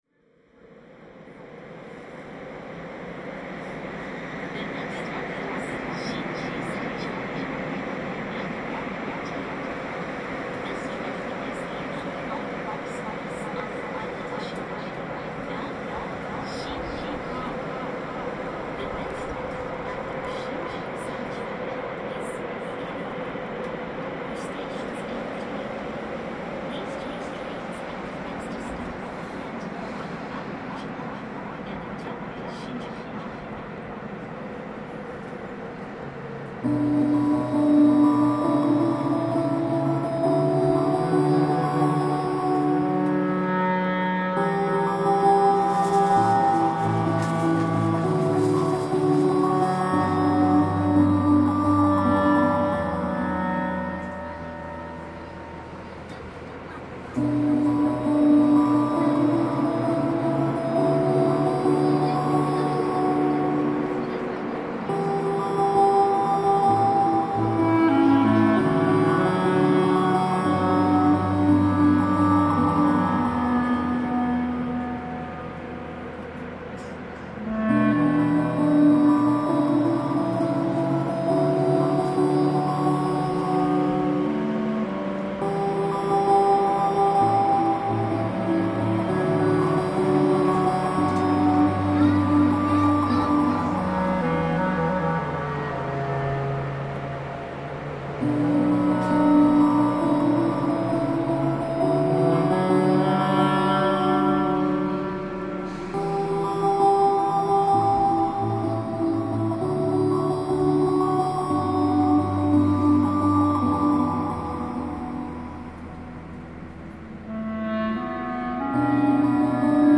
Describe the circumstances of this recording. Tokyo subway reimagined